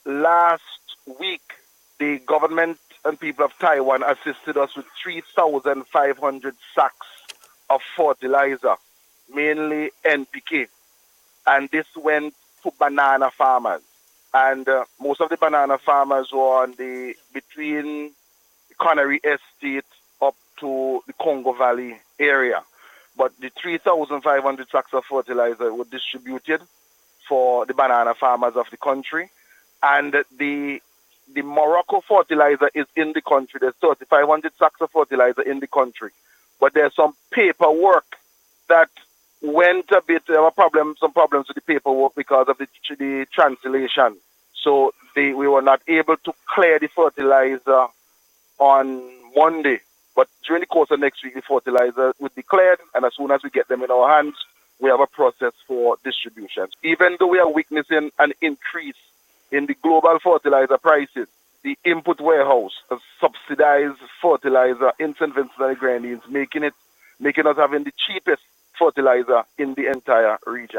This assurance came from Minister of Agriculture, Saboto Caesar, during NBC’s Face to Face programme this morning.